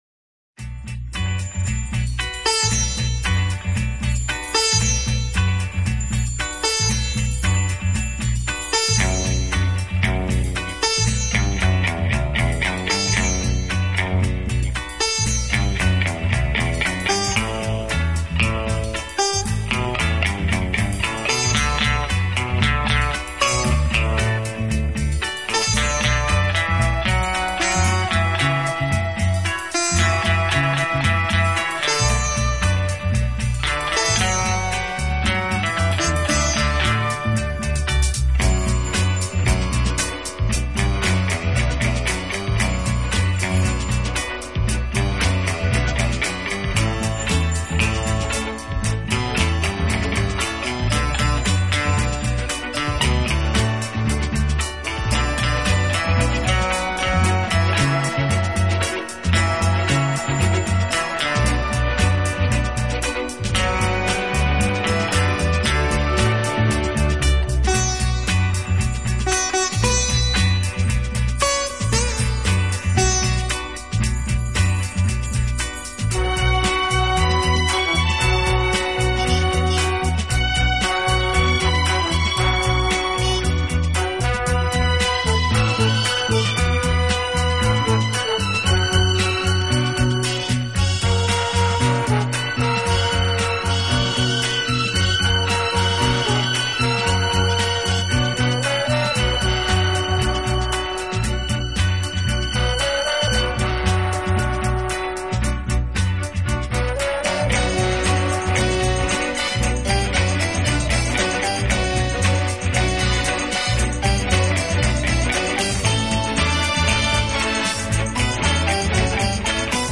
【轻音乐】
Genre: Instrumental / Easy Listening